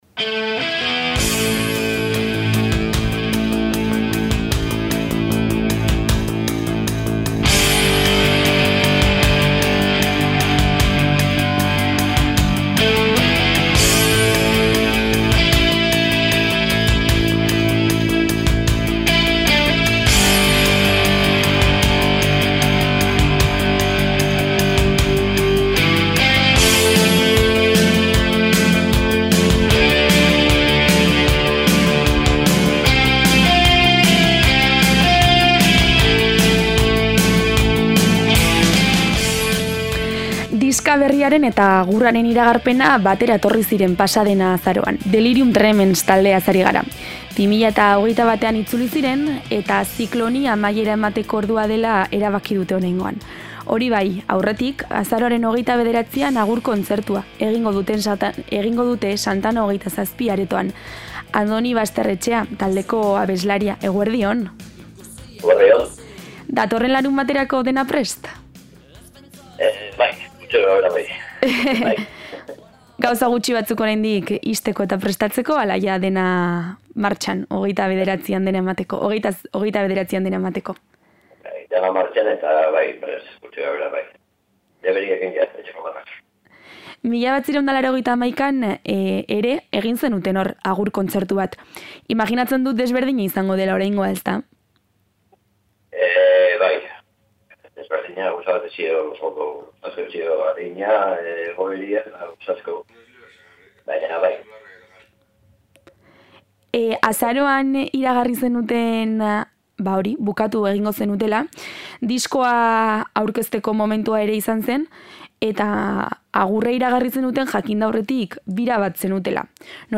aritu gara solasean eta argi adierazi du datorren larunbaterako dena prest dutela.